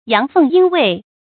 注音：ㄧㄤˊ ㄈㄥˋ ㄧㄣ ㄨㄟˊ
陽奉陰違的讀法